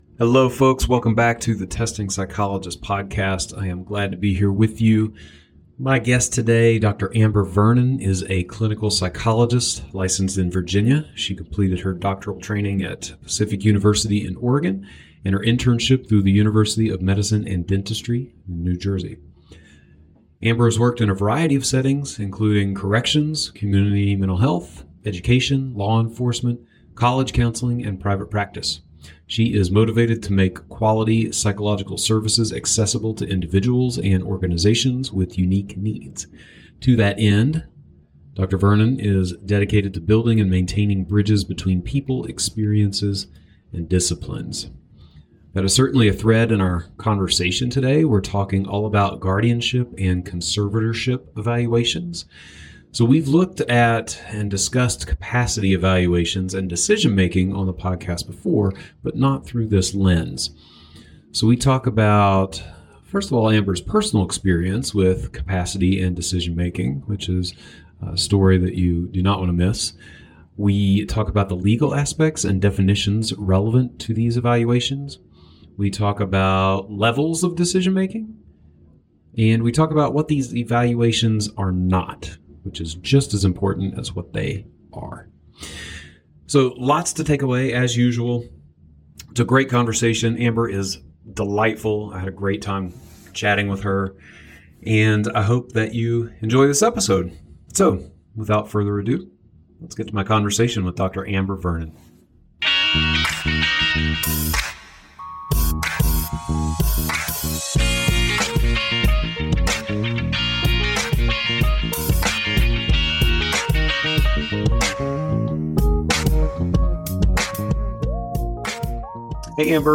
Interview conducted: November 2022